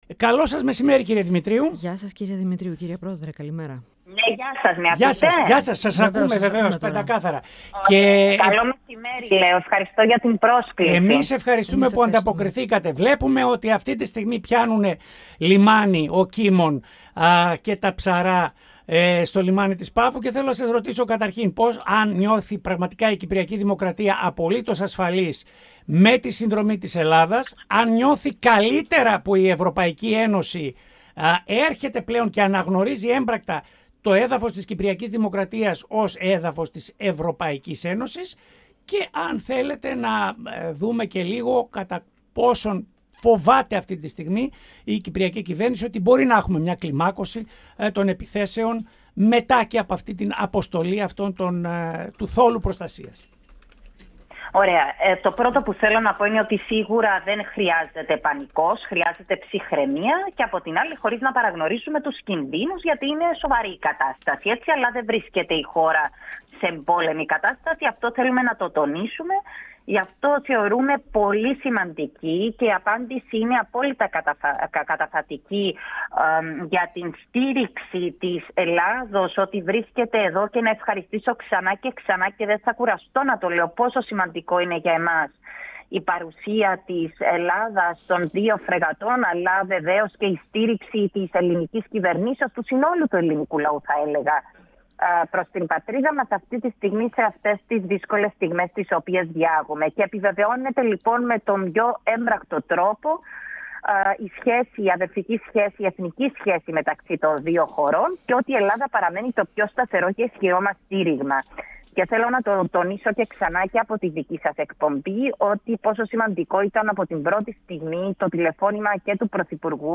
“Η πολυεπίπεδη εξωτερική πολιτική που χτίσαμε μαζί με την Ελλάδα, όλα αυτά τα χρόνια, δικαιώνεται τώρα με την αντίδραση της ΕΕ”, δήλωσε, μιλώντας στον 102fm, η πρόεδρος της Κυπριακής Βουλής, Αννίτα Δημητρίου.